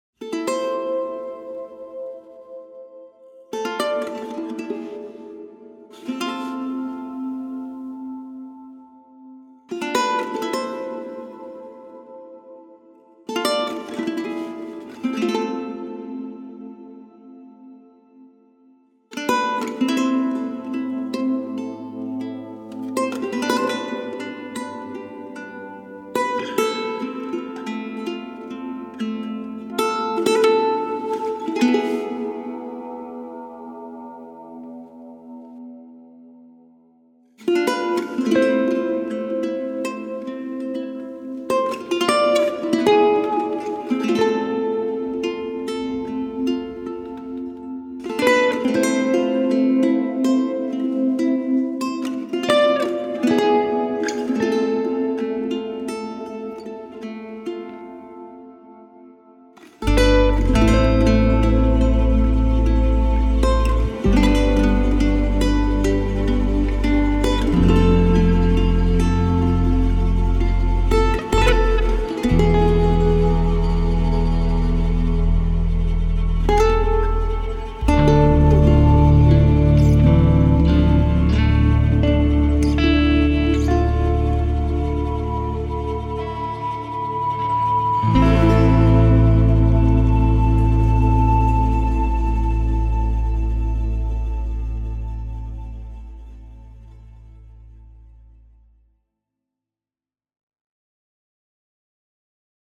حس و حال موسیقی اسپانیایی داشت